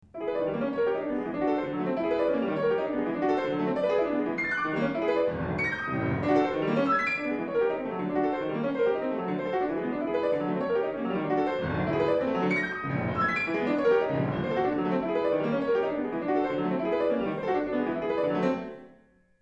I wrote a series of five extremely short piano pieces called each Possible World in 2005.
Possible World nr. 3 consists entirely of broken dominant seventh chords on D, Eb or E, going up or down in varying registers.